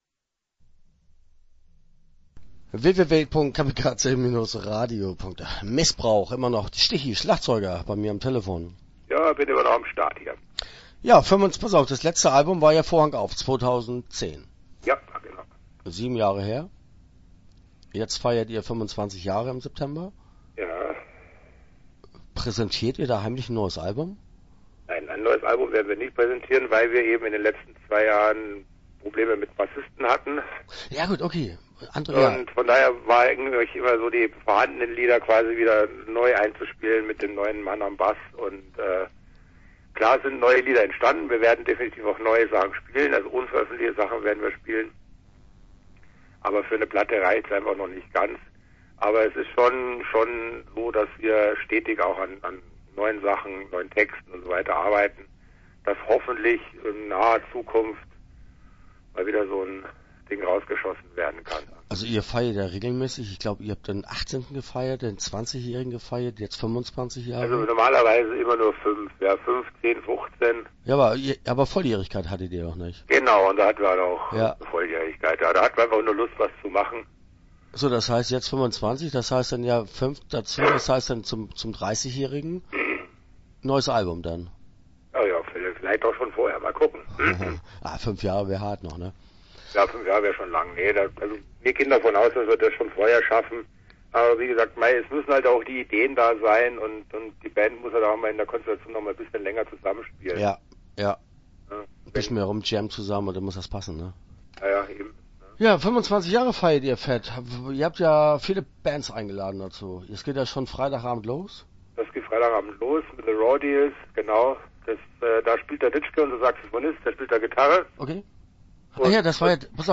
Missbrauch - Interview Teil 1 (9:04)